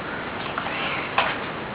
Capitol Theatre E.V.P.
This EVP is hard to make out, the first part sounds like "de"-something or other.